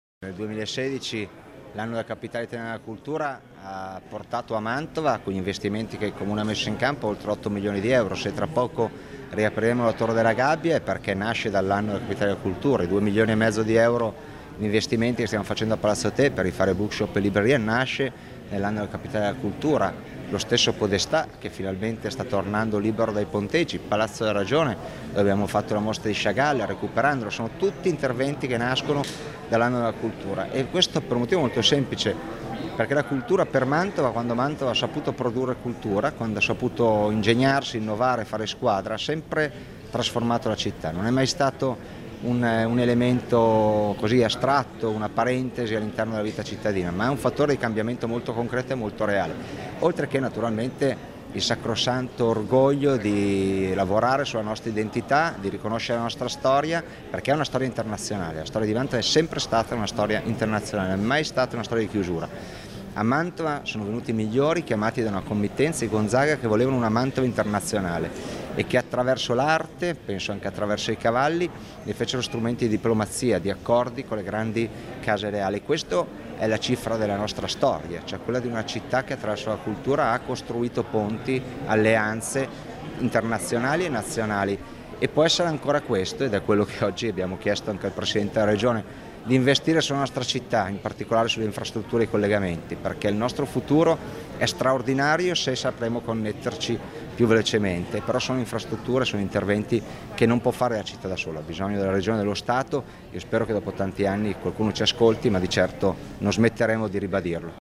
Presente alla conferenza stampa anche il sindaco di Mantova, Mattia Palazzi, che ha sottolineato come investire nella cultura significhi investire nella città: